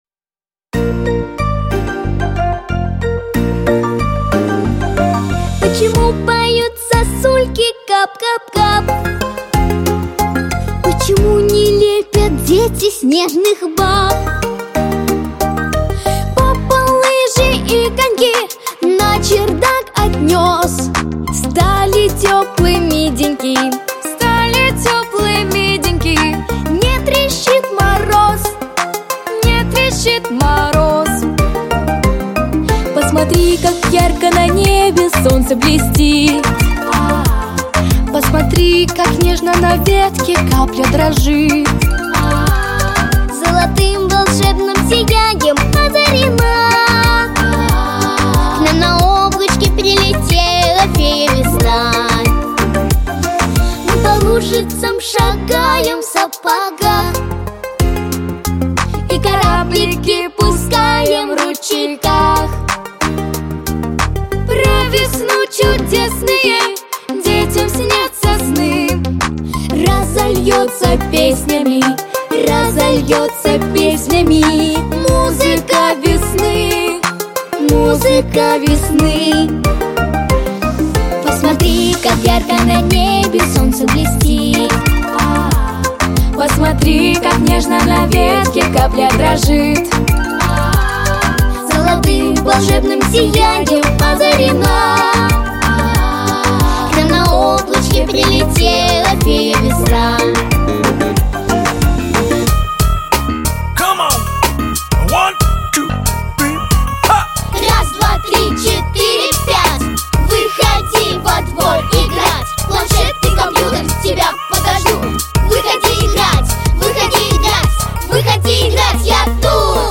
• Качество: Хорошее
• Жанр: Детские песни